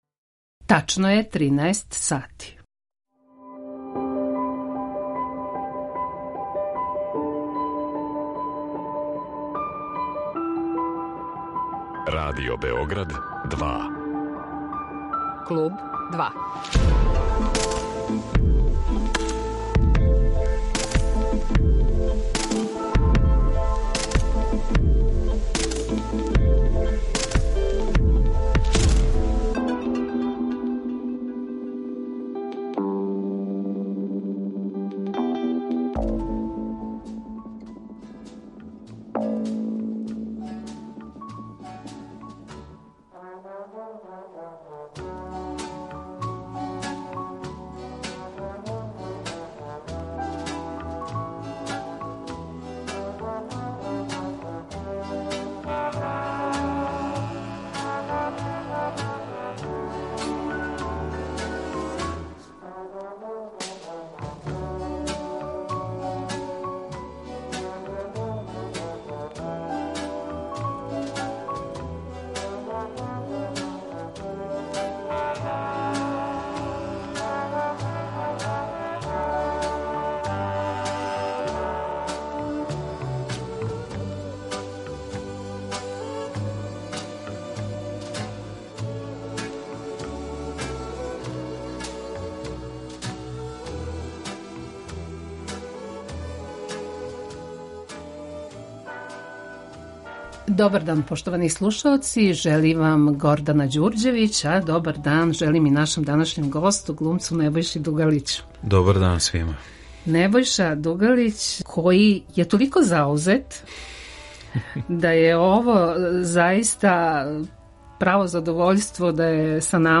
Гост Клуба 2 је један од наших најзначајнијих глумаца Небојша Дугалић